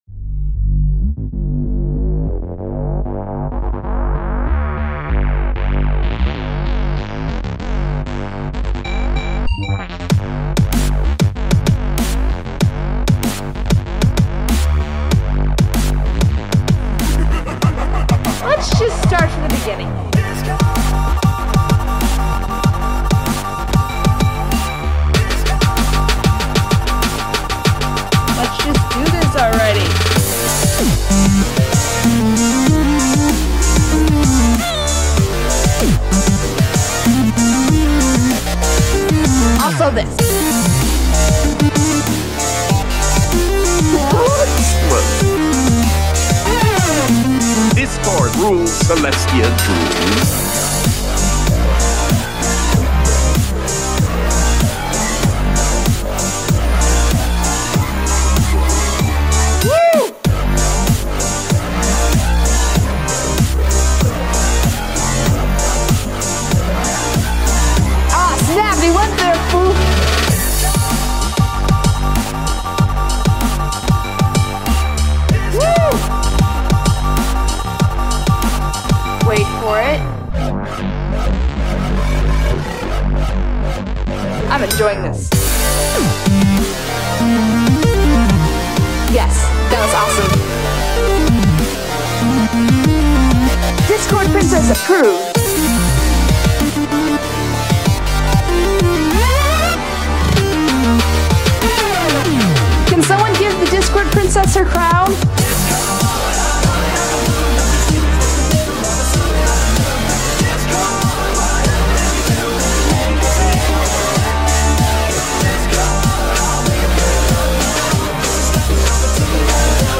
genre:dubstep
genre:remix